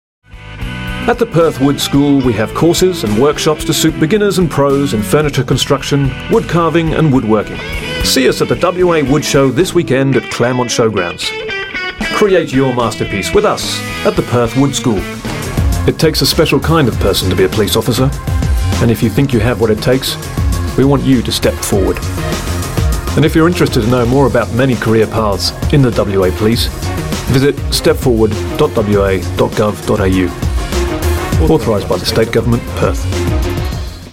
Voice Reel Demo